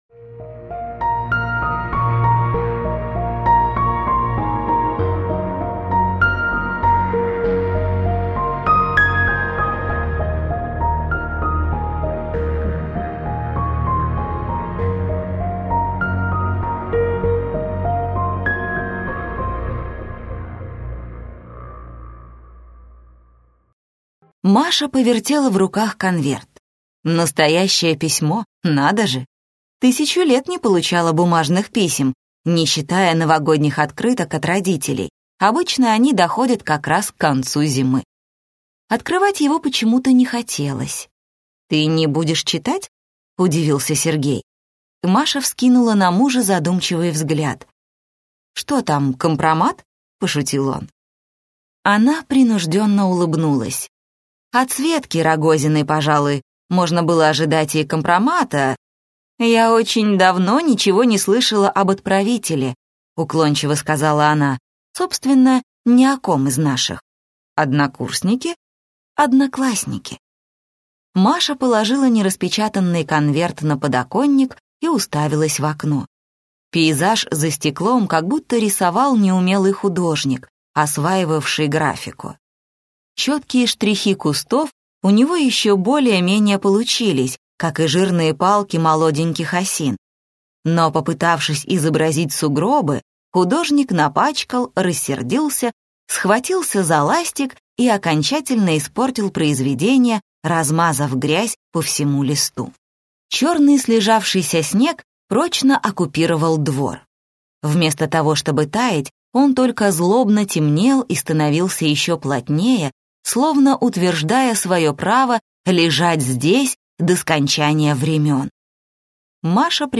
Аудиокнига Нежные листья, ядовитые корни - купить, скачать и слушать онлайн | КнигоПоиск